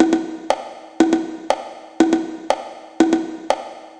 120_bongo_1.wav